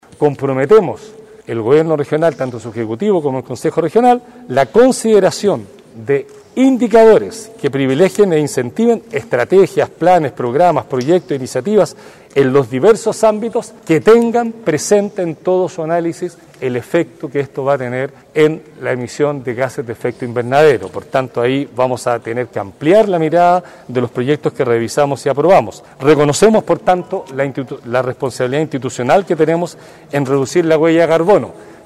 Dicho anuncio fue realizado en el edificio administrativo de Gobierno de la Región de Los Lagos por el Gobernador Patricio Vallespín en compañía de los Consejeros regionales en una declaración pública con la prensa, determinación que va de la mano con la agenda que impulsará el Gobierno regional.